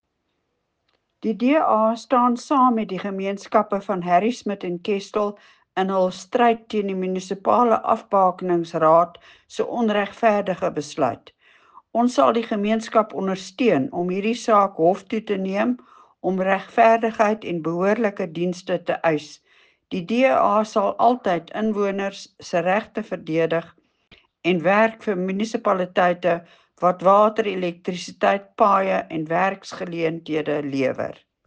Afrikaans soundbites by Cllr Leona Kleynhans and